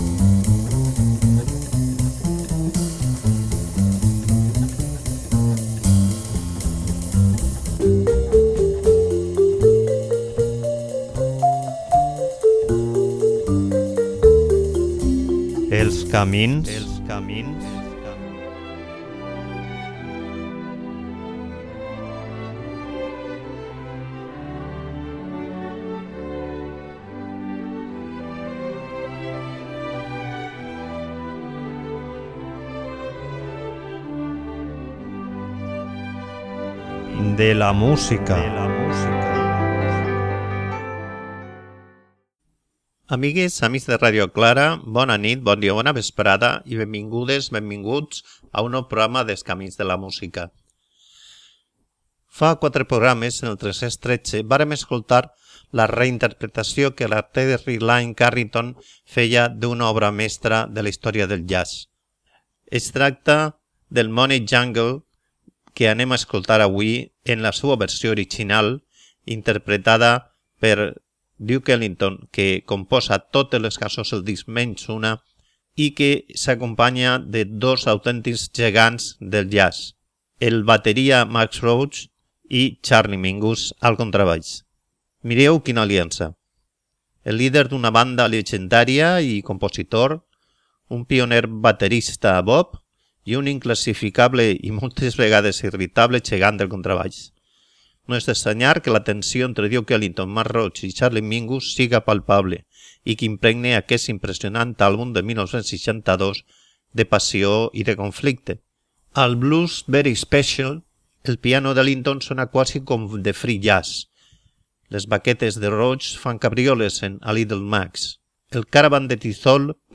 bateria